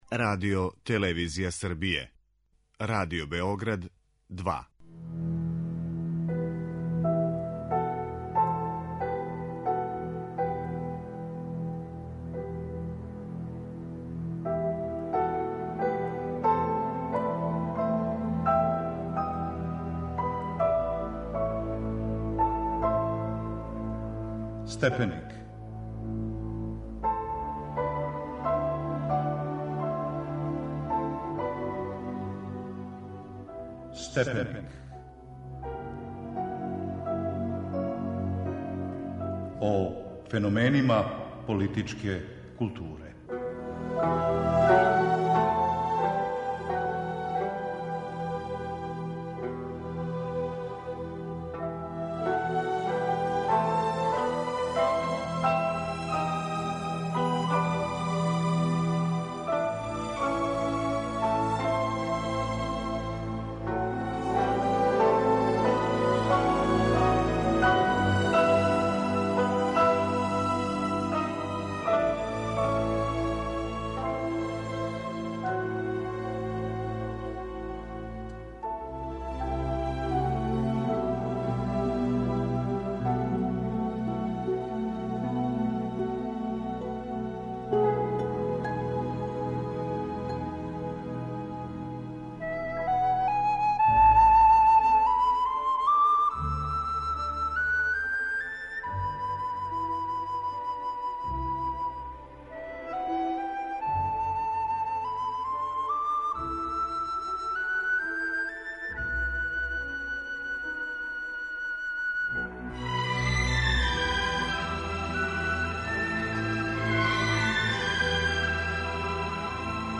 О књизи Стивена Пинкера "Просвећени свет" у данашњем Степенику разговарамо